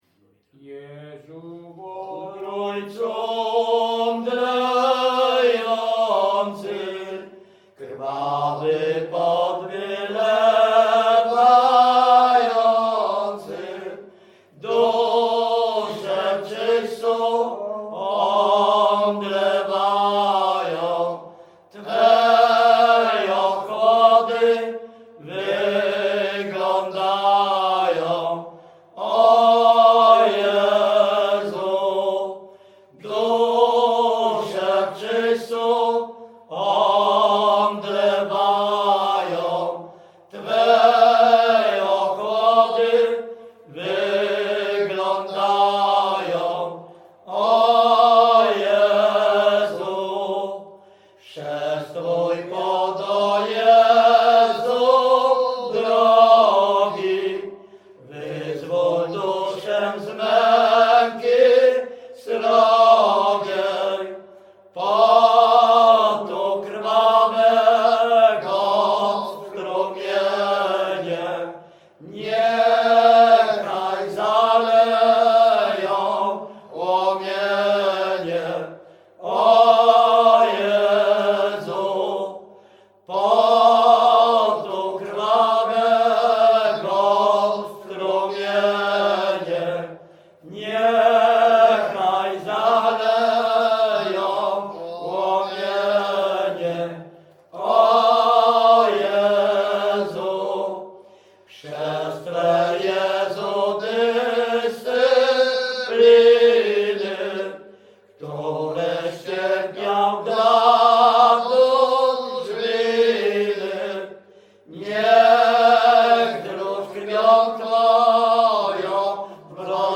Śpiewacy z Ruszkowa Pierwszego
Wielkopolska, powiat kolski, gmina Kościelec, wieś Ruszków Pierwszy
Wielkopostna
pogrzebowe nabożne katolickie wielkopostne